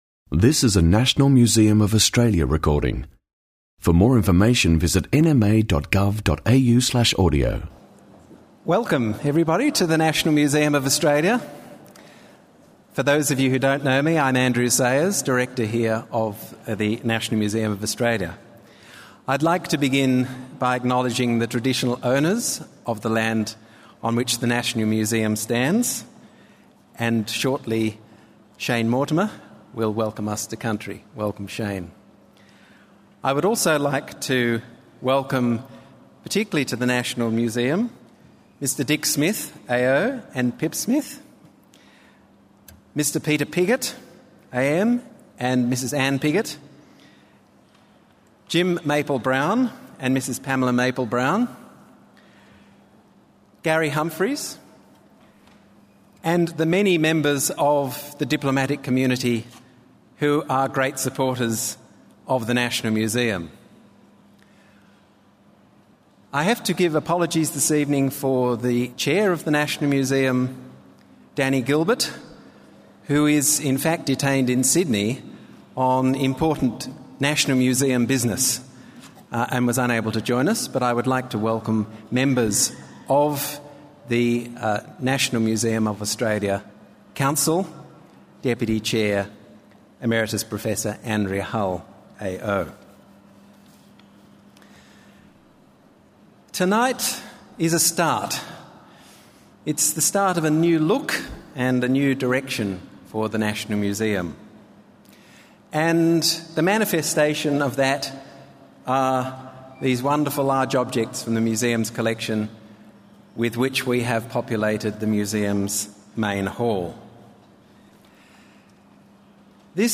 Big objects and Museum Workshop: exhibition opening | National Museum of Australia